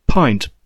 The pint (/ˈpnt/,